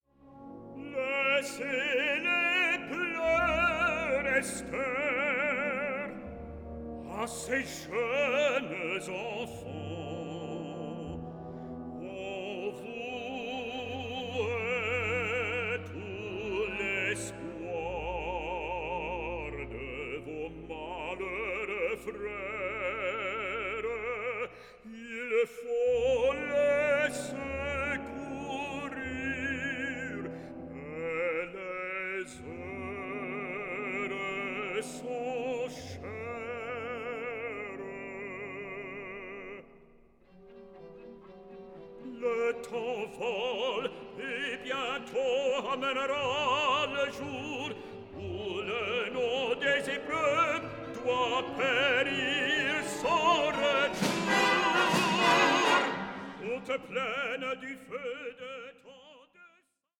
Choeur des femmes